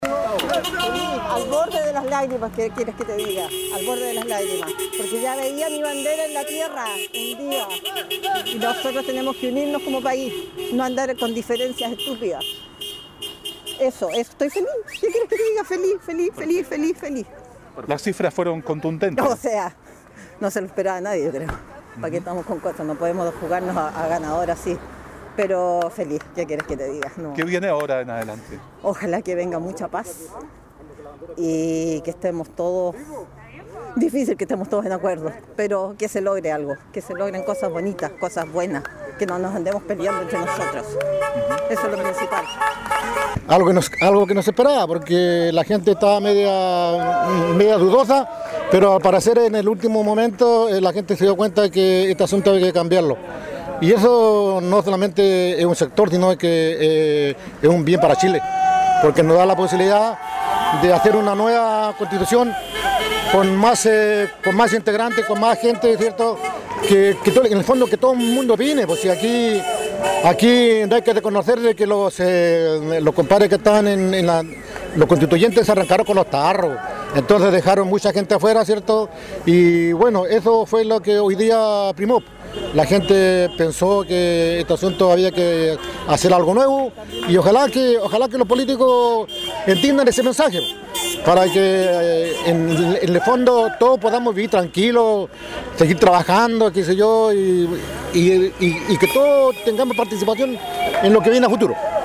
Luego que las cifras marcaran un histórico triunfo del Rechazo en el Plebiscito Constitucional de este domingo, las calles de las diferentes ciudades fueron escenario de las celebraciones de los adherentes, con los automóviles haciendo sonar sus sirenas y el ondear de banderas chilenas.
Es el caso de Ancud, donde cerca de las 20 horas comenzó un grupo de personas a manifestarse por la victoria con una diferencia muy importante en todo el país.